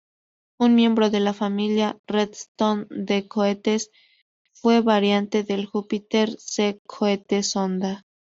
Pronounced as (IPA) /ˈmjembɾo/